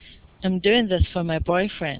There are commonly used supra-segmental features in everyday American English conversation, which makes the target language fast and fluent.
And they are pronounced in very short and weak sounds.
for                      fe